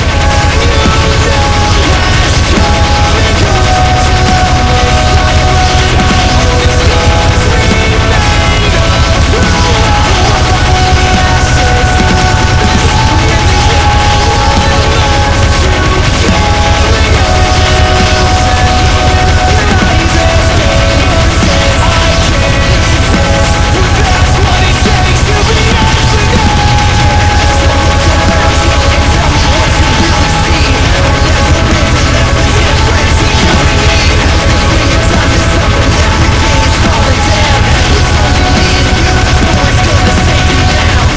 minifun_shoot_crit.wav